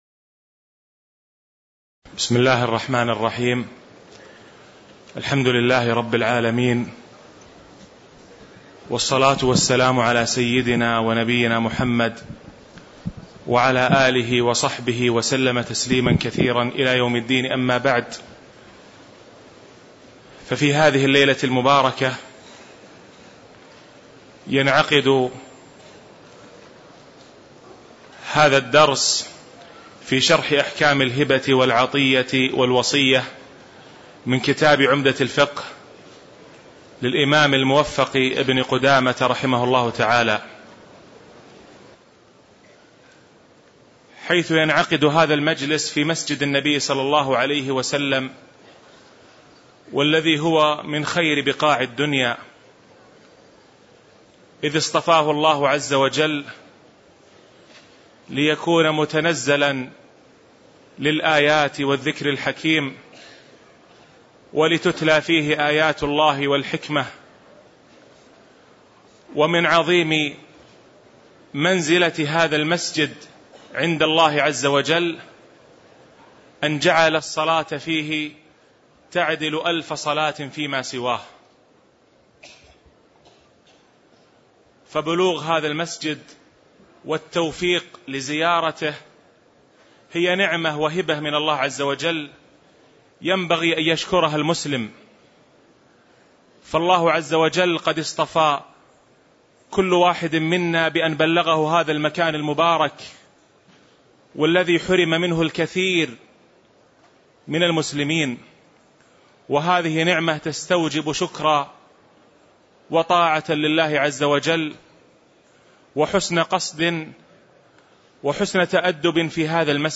تاريخ النشر ٢٩ رجب ١٤٣٧ هـ المكان: المسجد النبوي الشيخ